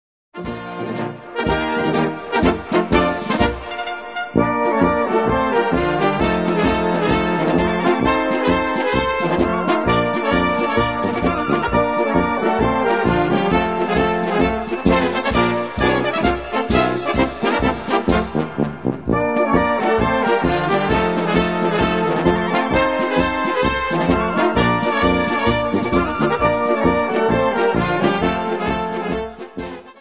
Gattung: Marschpolka
Besetzung: Blasorchester